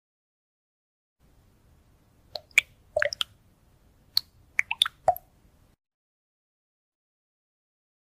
I Need A Slime Bowl Sound Effects Free Download